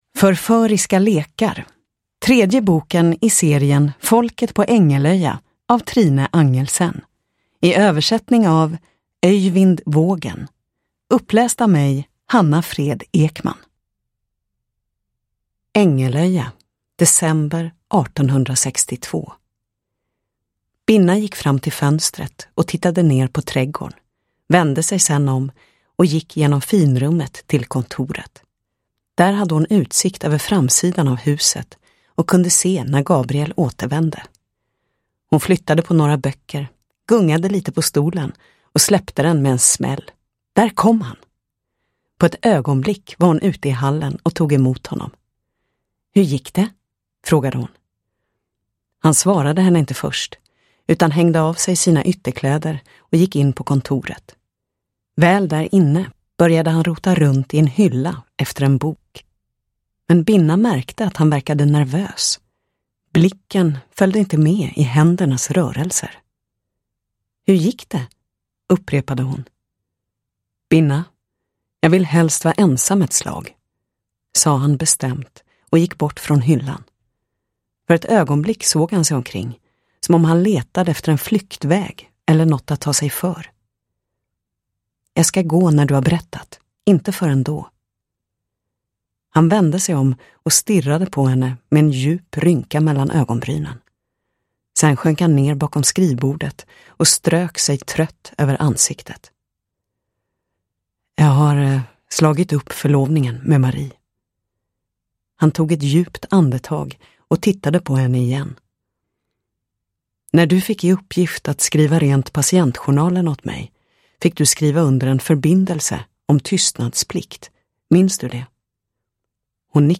Förföriska lekar – Ljudbok – Laddas ner